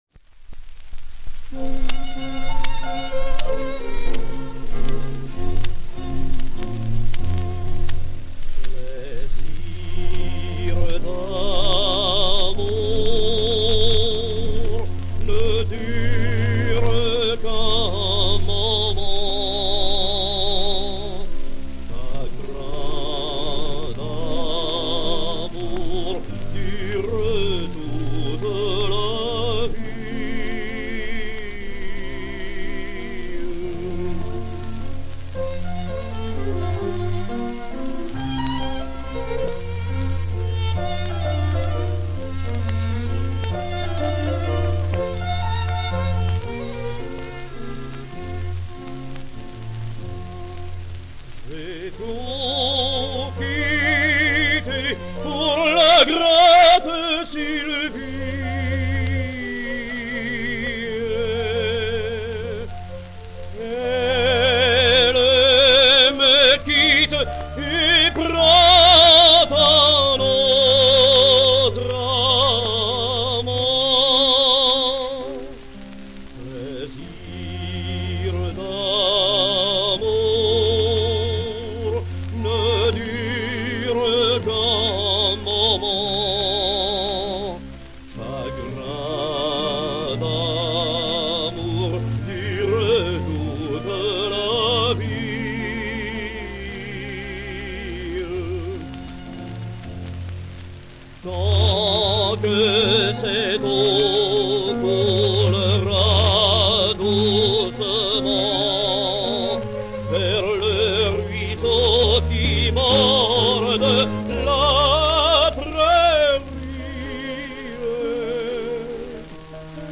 He must certainly have retired when leaving the Opéra-Comique; his electrical recordings prove that his voice was a wreck at that time.
Consortium, Paris, 1933 or 1934